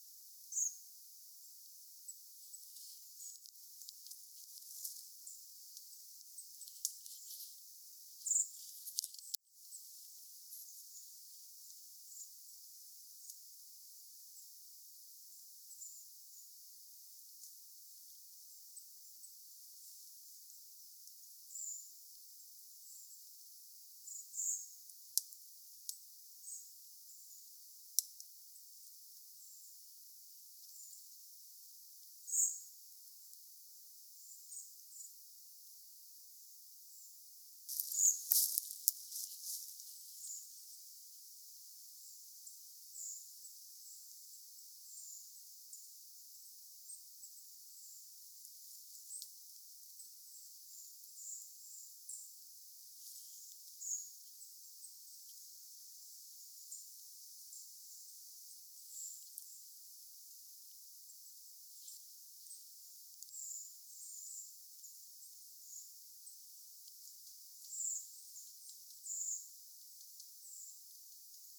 keltanokkarastaslintujen ääniä
keltanokkarastaslintujen_aania.mp3